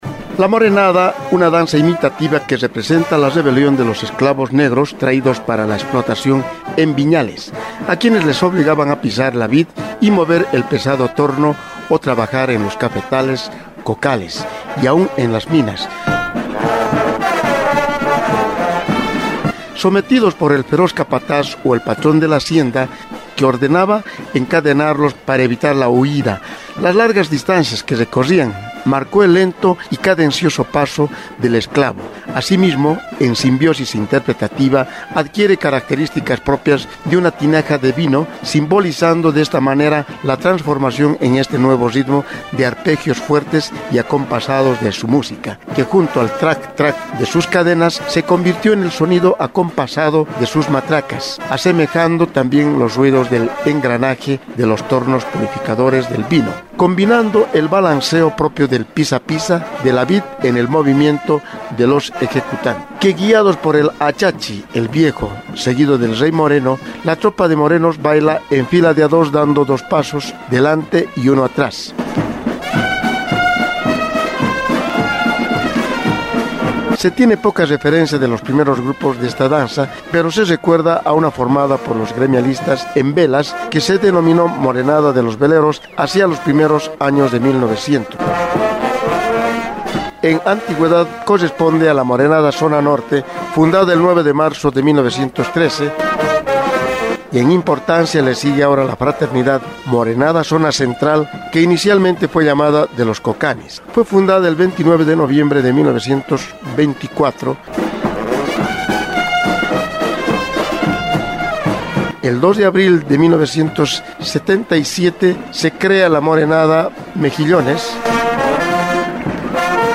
Carnaval de Oruro
La Morenada
La música de la Morenada es cadenciosa y alegre. Inicialmente, fue acompañada por instrumentos de viento originarios, pero ahora las bandas de instrumentos metálicos han aportado mayor prestancia y sonoridad a la música.
morenada.mp3